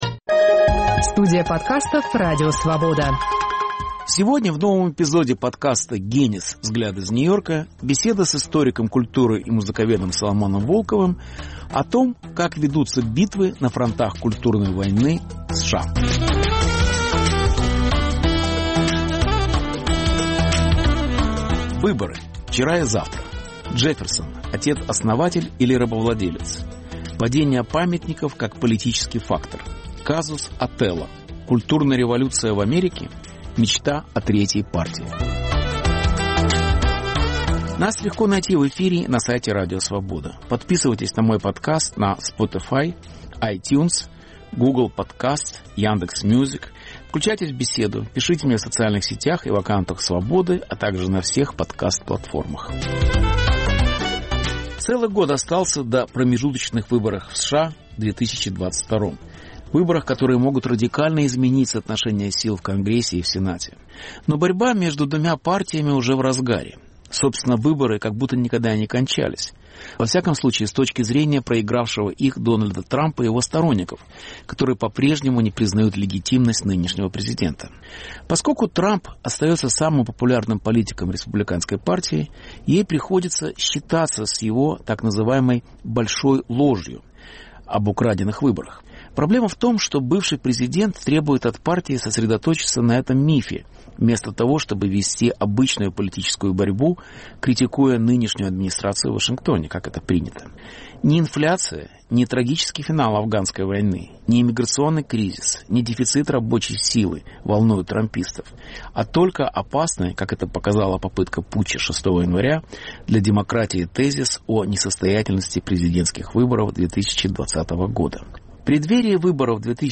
Беседа с Соломоном Волковым об идеологической борьбе в США